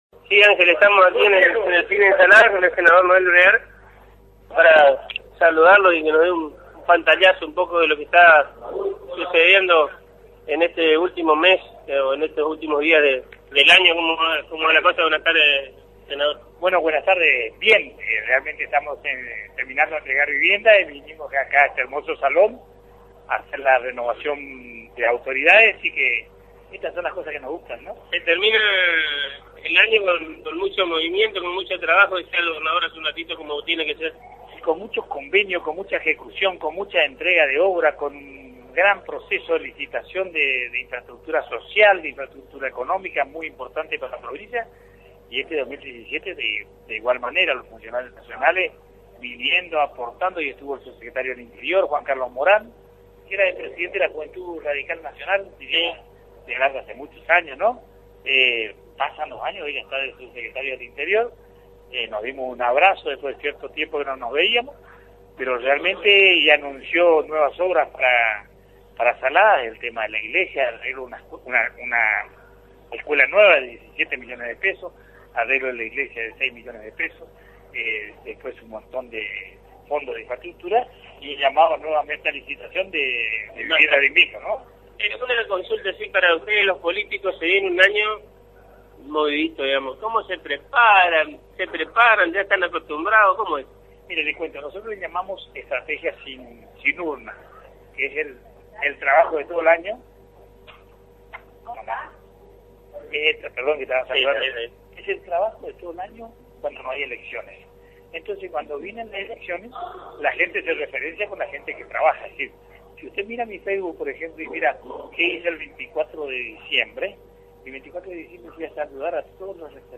(Audio) Así lo analizó el senador provincial por la UCR Noel Breard en contacto con el equipo de exteriores de Agenda 970 por Radio Guarani.